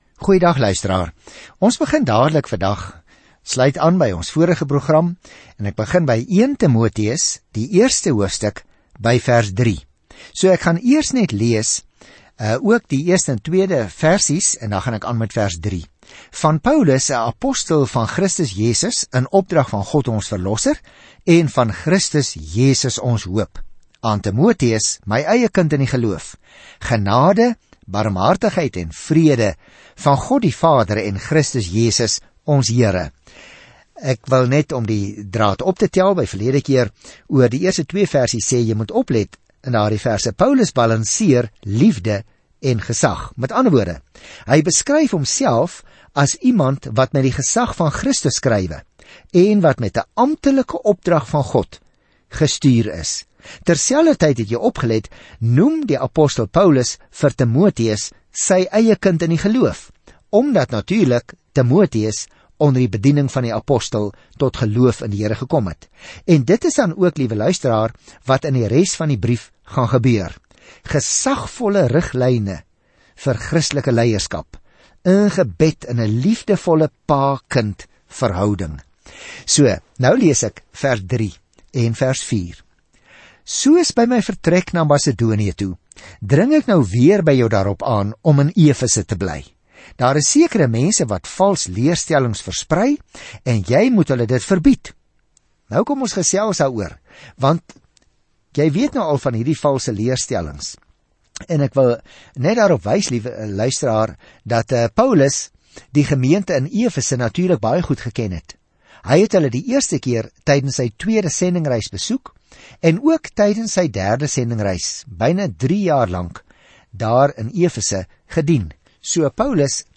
Skrif 1 TIMOTEUS 1:3-5 Dag 1 Begin met hierdie leesplan Dag 3 Aangaande hierdie leesplan Die eerste brief aan Timoteus verskaf praktiese aanduidings dat iemand verander is deur die evangelie-ware tekens van godsaligheid. Reis daagliks deur 1 Timoteus terwyl jy na die oudiostudie luister en uitgesoekte verse uit God se woord lees.